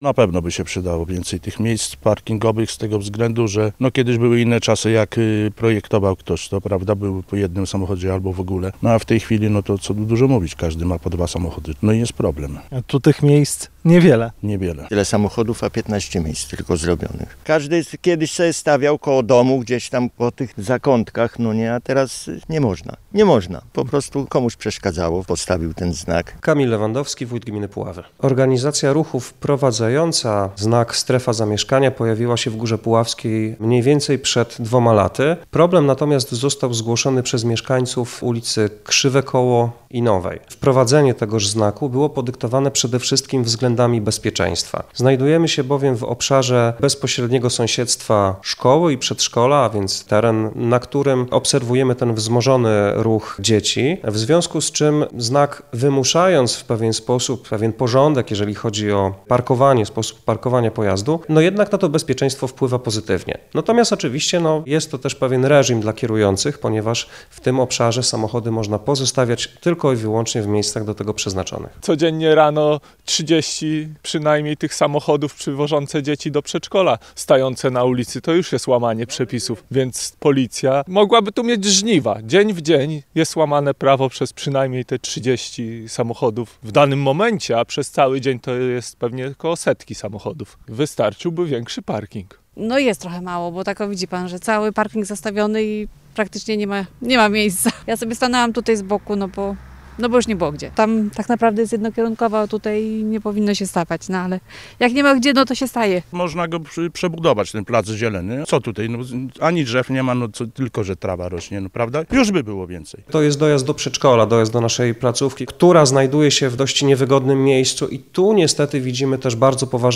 – To po jego ustawieniu pojawił się problem – mówią mieszkańcy w rozmowie z naszym reporterem.